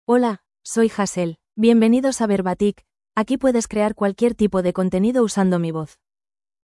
FemaleSpanish (Spain)
Hazel is a female AI voice for Spanish (Spain).
Voice sample
Listen to Hazel's female Spanish voice.
Hazel delivers clear pronunciation with authentic Spain Spanish intonation, making your content sound professionally produced.